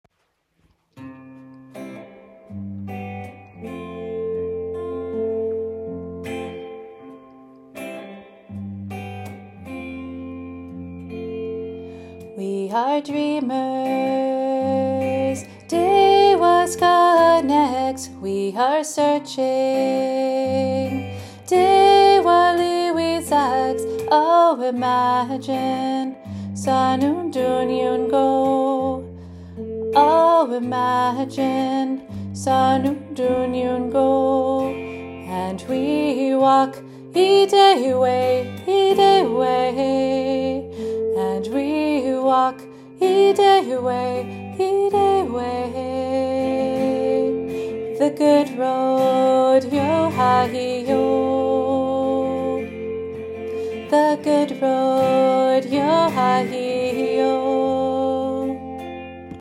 Good-Road-with-new-rhythm-2.m4a